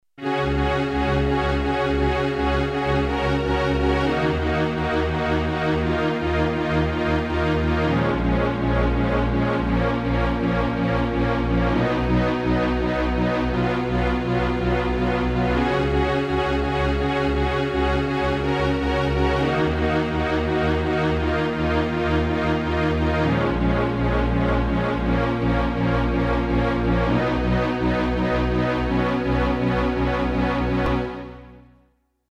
demo HEAR modulation on strings
strings2.mp3